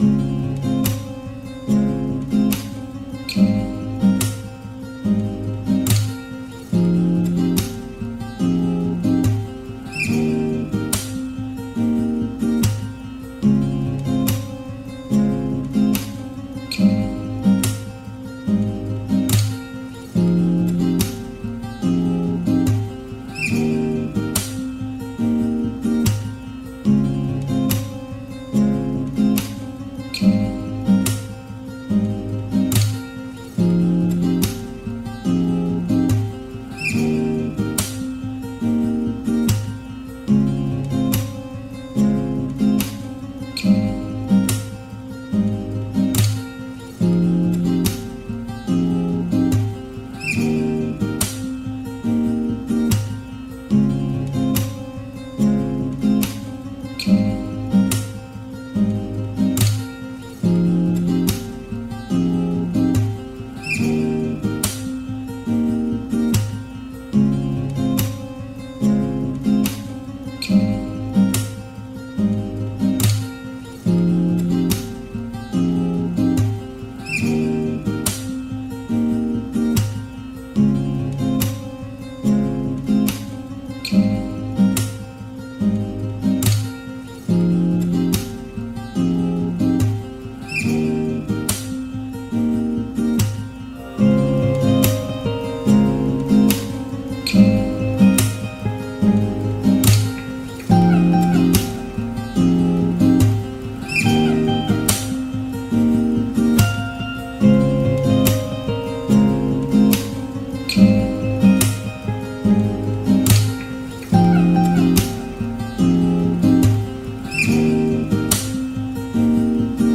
this is the instrumental for it